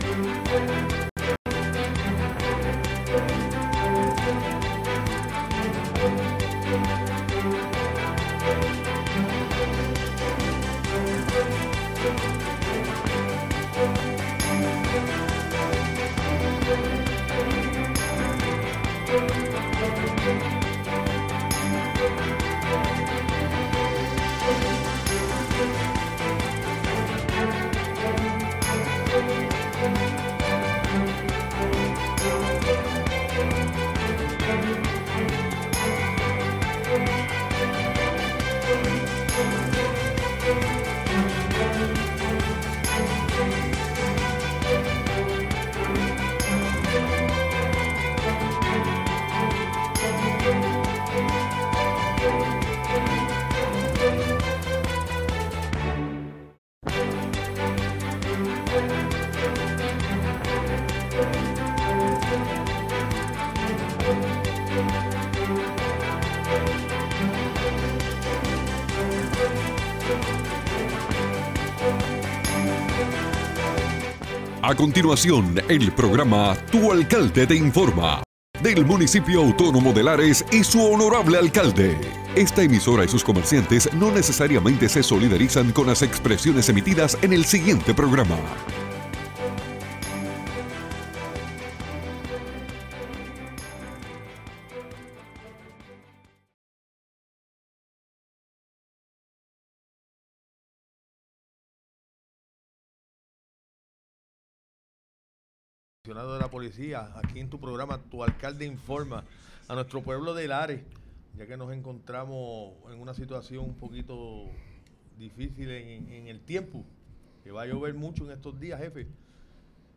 El honorable alcalde de Lares, Fabián Arroyo, junto a su equipo de trabajo nos informan sobre todo las novedades del municipio.